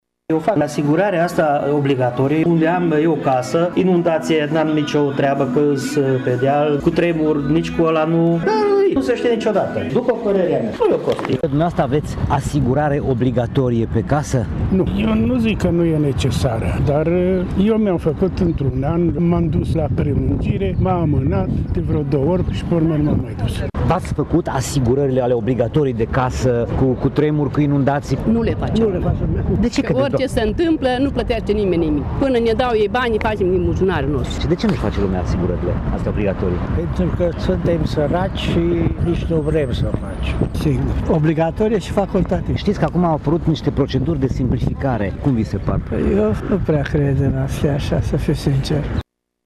Această simplificare birocratică nu îi interesează pe târgumureșenii întâlniți de reporterul RTM, unii chiar recunosc că nu au nici măcat asigurarea obligatorie de case: